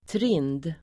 Ladda ner uttalet
trind adjektiv (om personer " fet, knubbig"), roundish [used of people, " fat, chubby"] Uttal: [trin:d] Böjningar: trint, trinda Synonymer: fyllig, knubbig, rund, rundhyllt, rundlagd, tjock Definition: klotrund